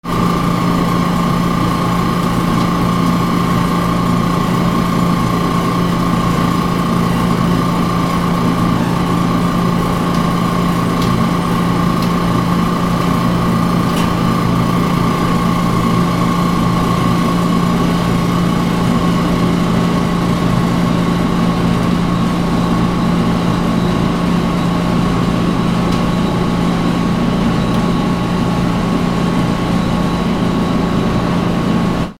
乾燥機
/ M｜他分類 / L10 ｜電化製品・機械